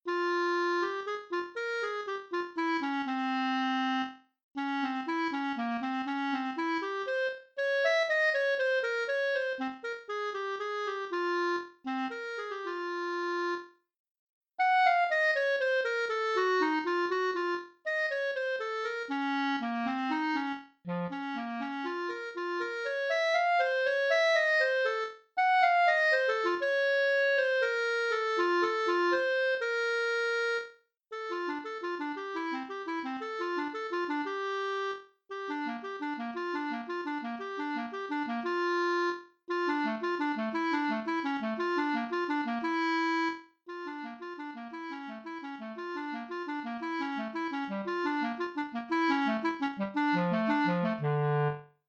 This is a playful solo piece for Bb Clarinet.